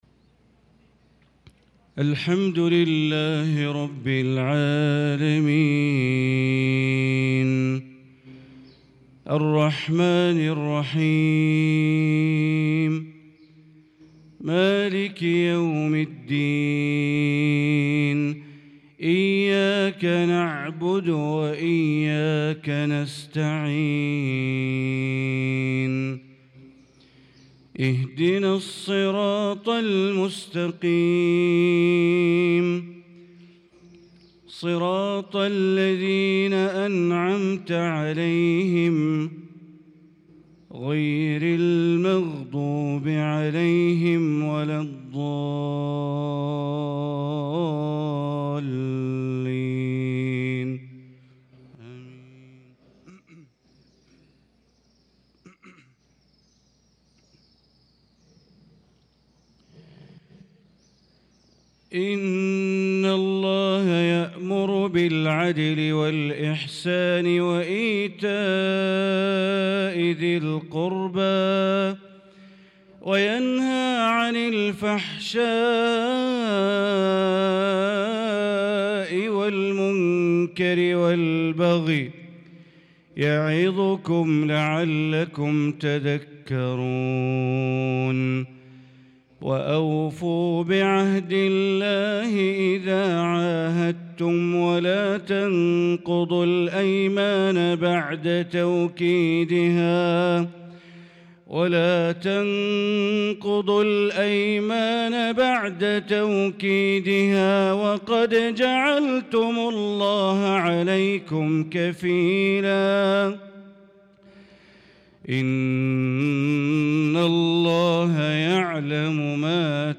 صلاة الفجر للقارئ بندر بليلة 2 شوال 1445 هـ